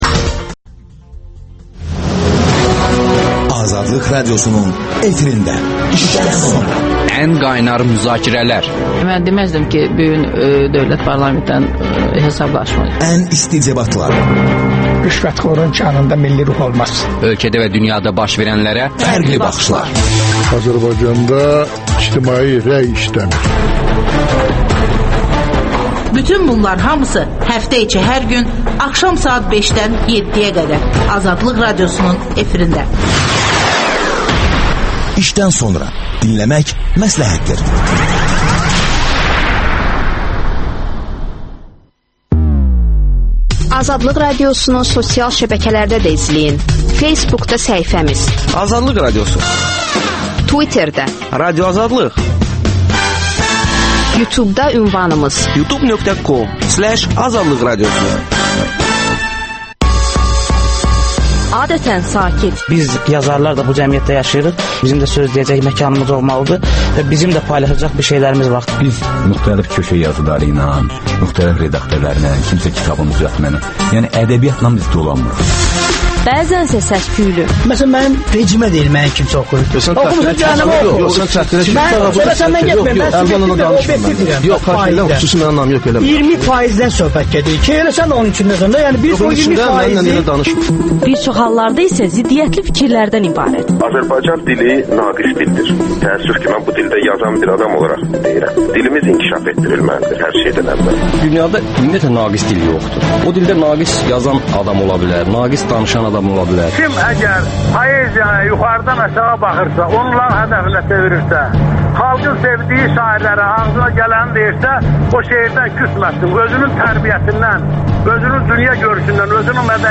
Milli Məclisin Təhlükəsizlik və müdafiə komitəsinin üzvü, deputat Aqil Abbas suallara cavab verir